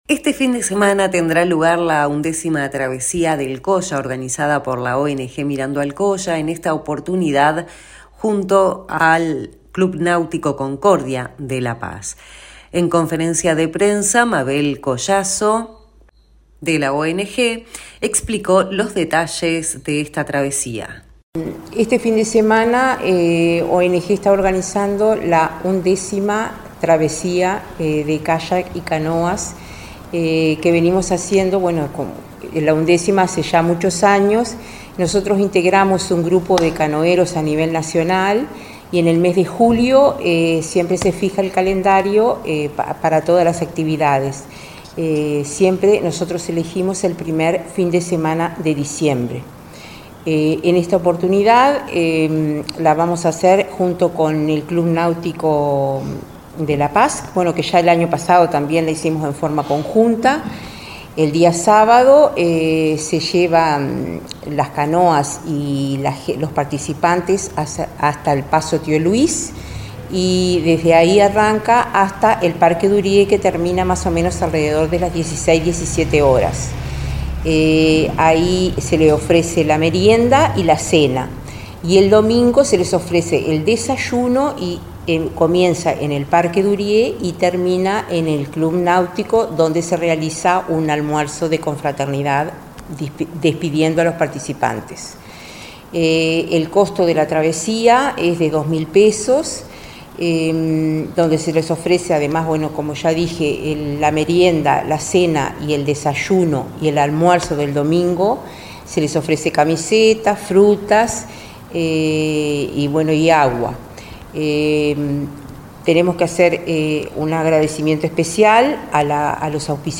En conferencia de prensa realizada en el Municipio de Rosario